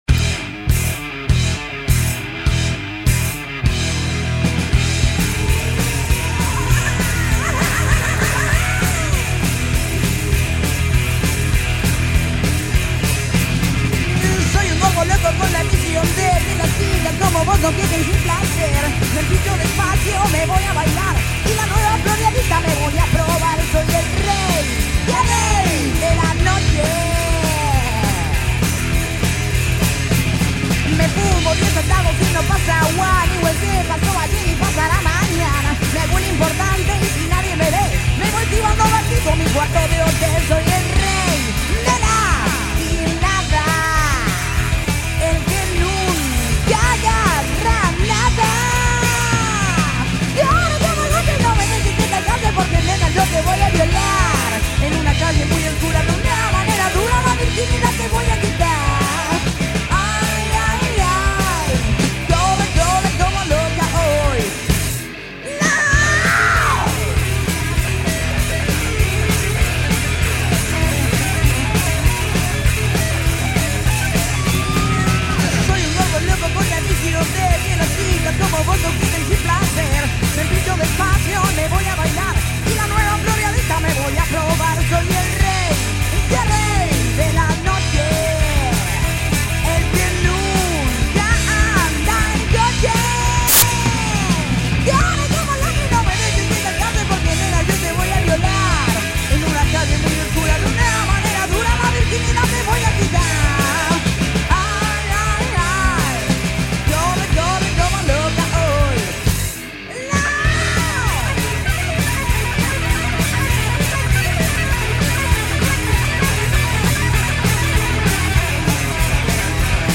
guitarra y coros
batería
bajo